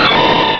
Cri de Chapignon dans Pokémon Rubis et Saphir.
Cri_0286_RS.ogg